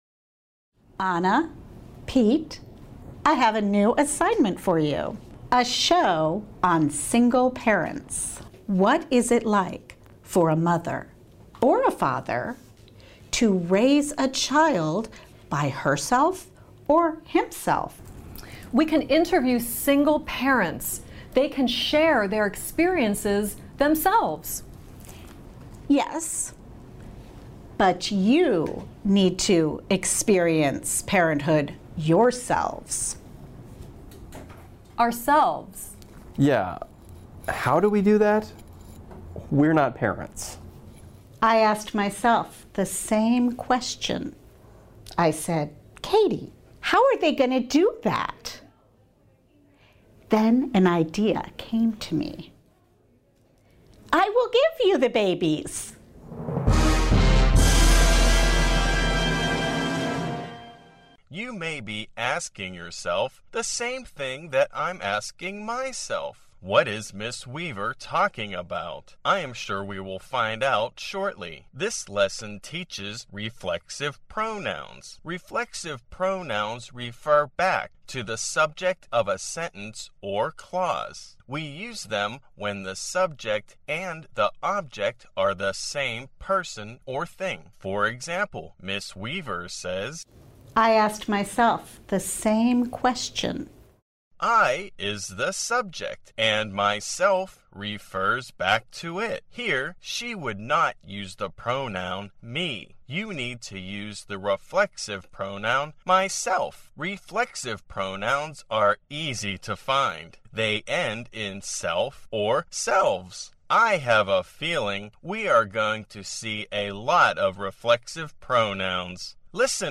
Hội thoại (Conversation)